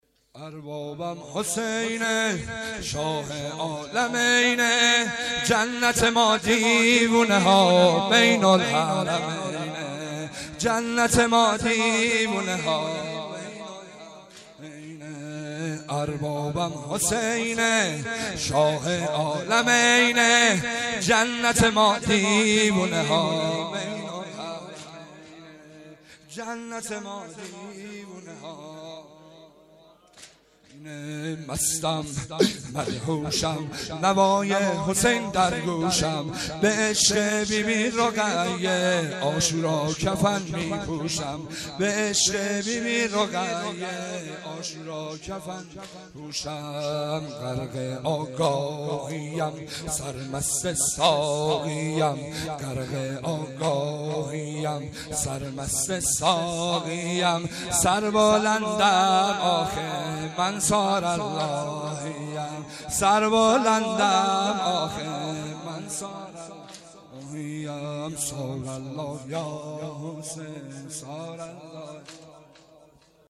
اجتماع لبیک یا زینب هفتگی